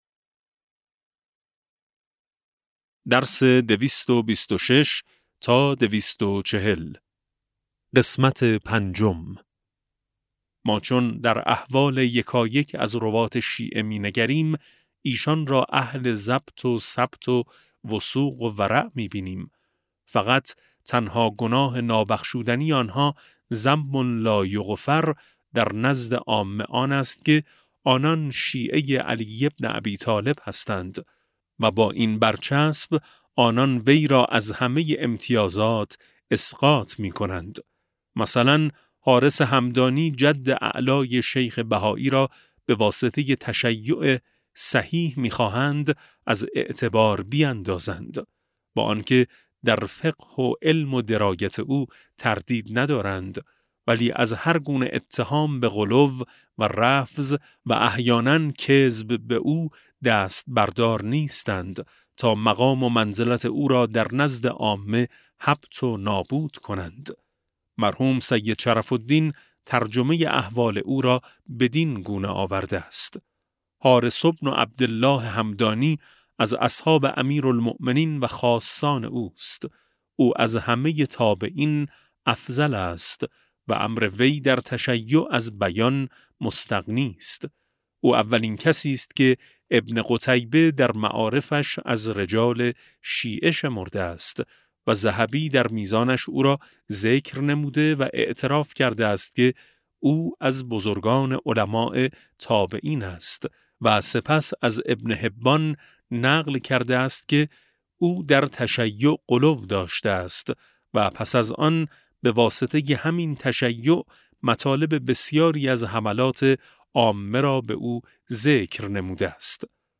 کتاب صوتی امام شناسی ج 16 و17 - جلسه5